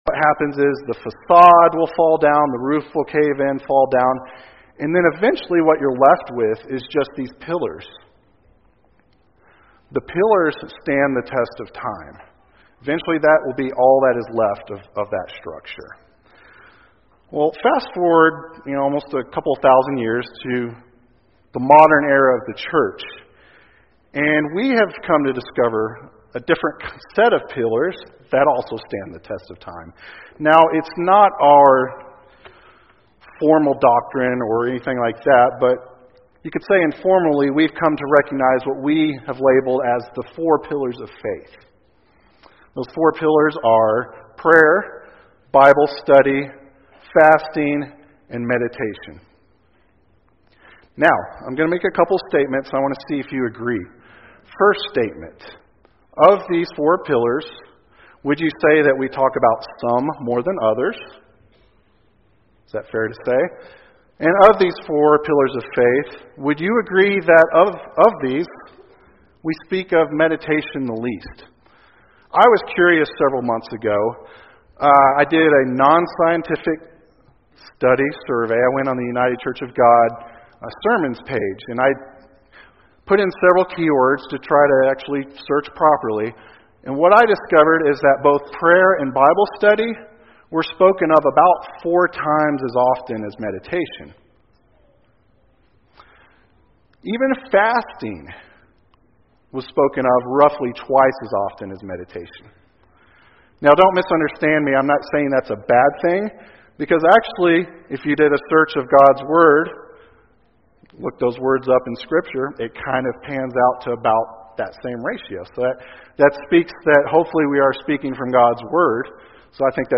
This sermon was given at the Glacier Country, Montana 2018 Feast site.